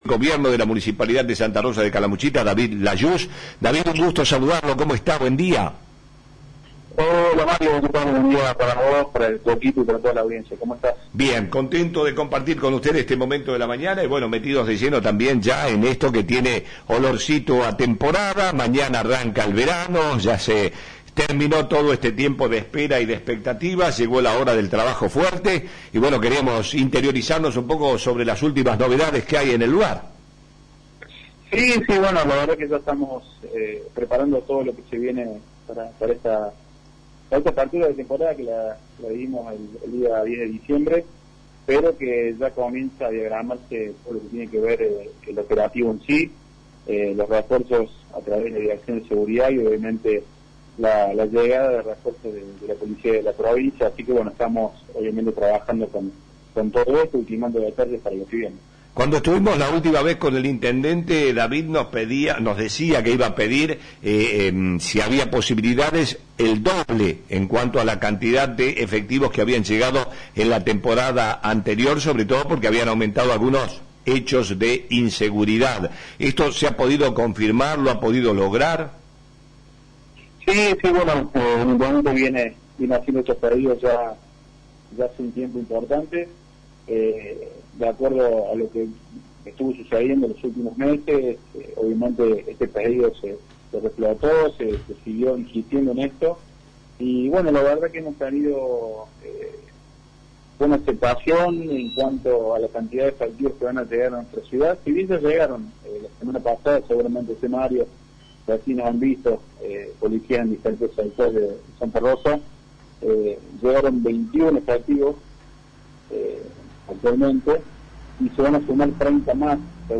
Lo confirmó hoy en nuestra radio el Secretario de Gobierno, quien se refirió a la situación actual en la ciudad, a la implementación del pase sanitario y también a los trabajaos de desmalezamiento que se realizan para mejorar la situación en los diferentes barrios. Además aclaró que las denuncias por venta y uso de pirotecnia se deben realizar al teléfono 421964.